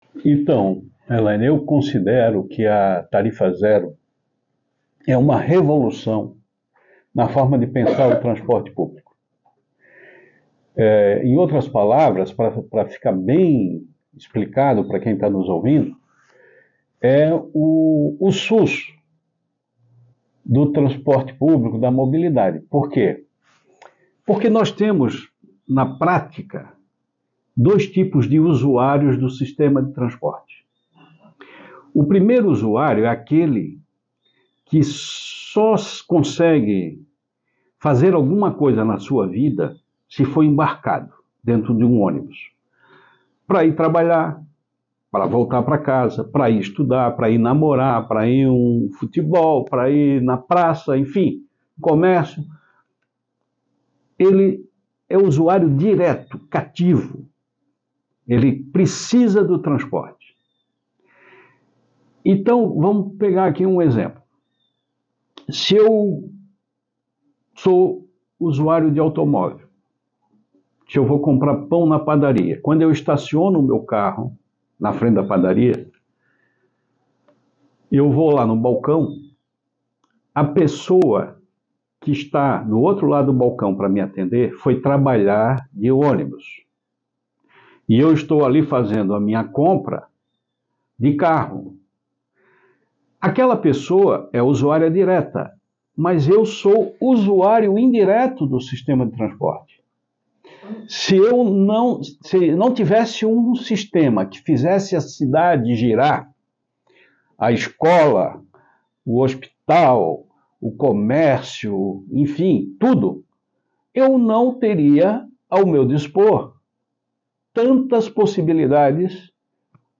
O vereador Afrânio Boppré explica como é possível garantir a tarifa zero na cidade.